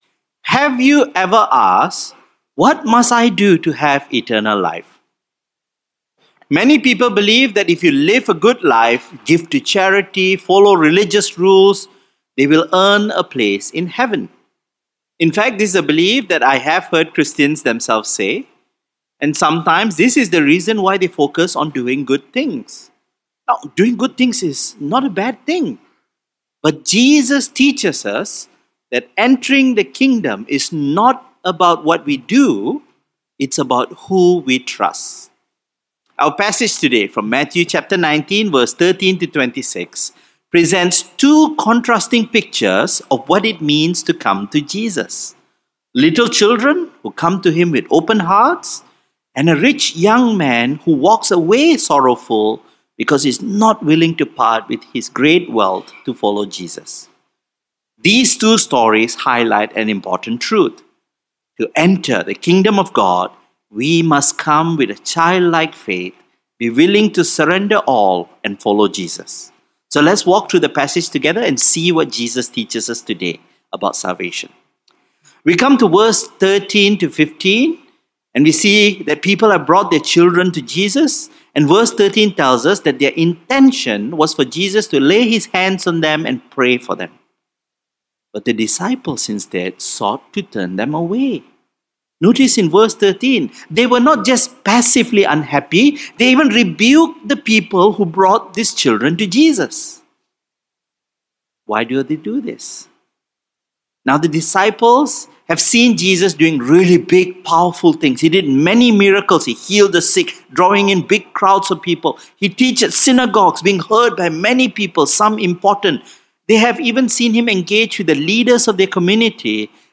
A weekly sermon from the English service at St Mary's Anglican Cathedral, Kuala Lumpur.